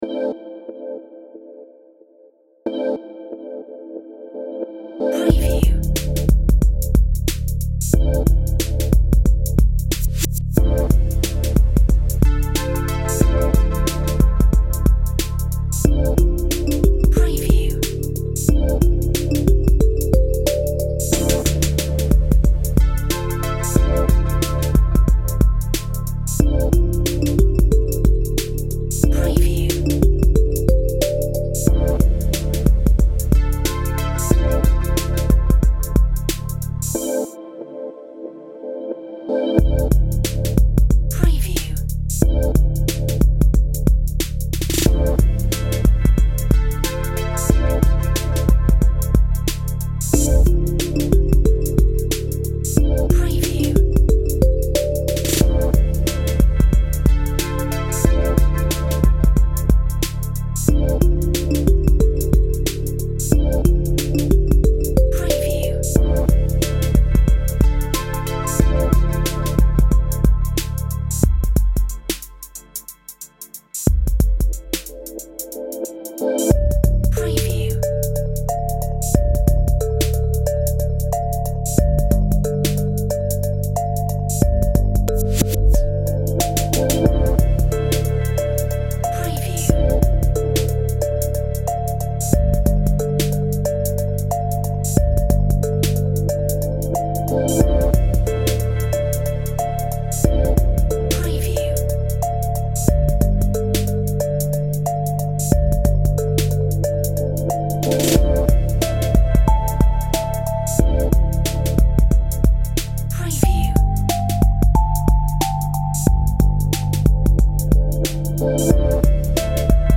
Chilled relaxation